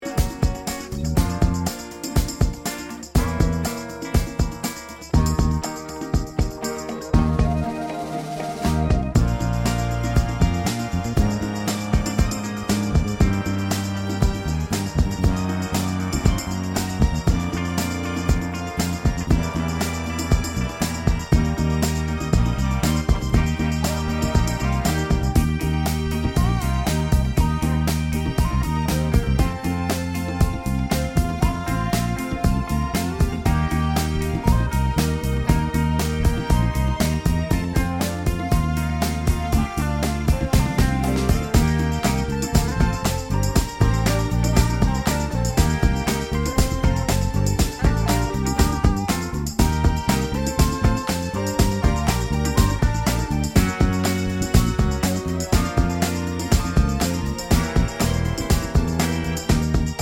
no Backing Vocals Soul / Motown 3:25 Buy £1.50